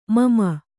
♪ mama